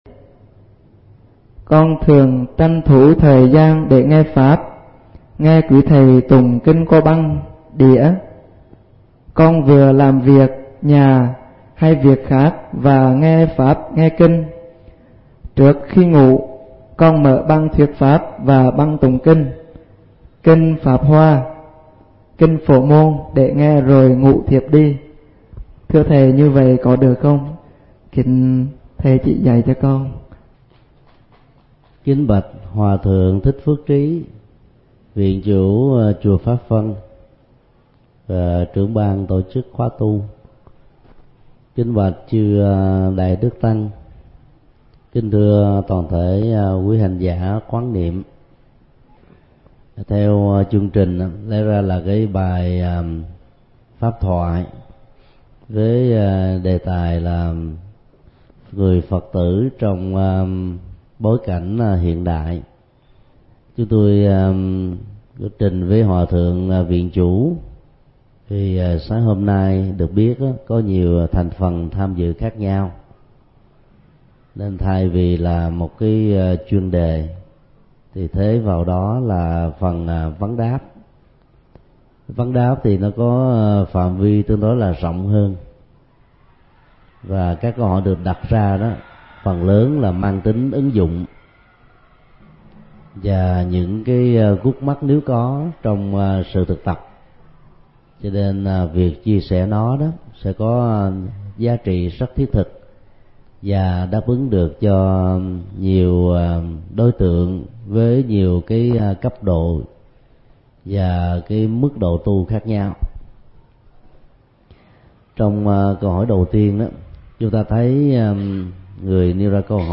Nghe mp3 Vấn đáp: Nghe kinh và thuyết pháp trong đời sống hằng ngày như thế nào – Thượng Tọa Thích Nhật Từ